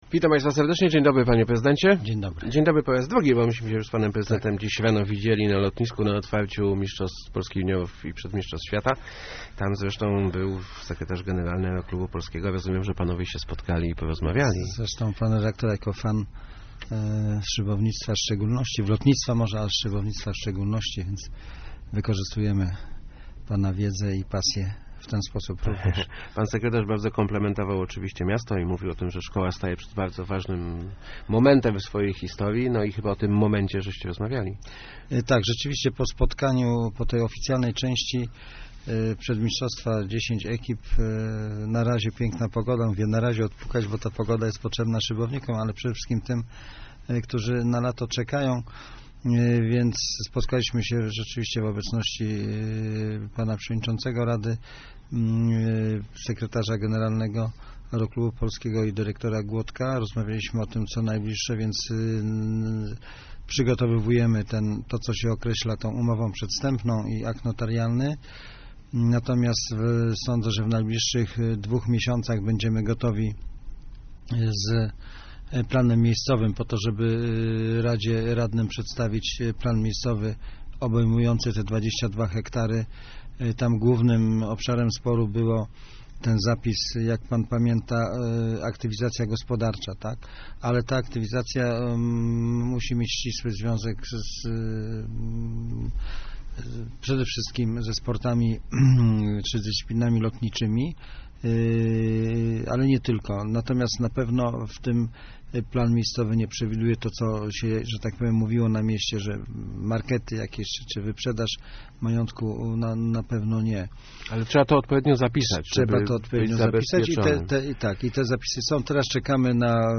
Gościem Kwadransa Samorządowego jest prezydent Tomasz Malepszy ...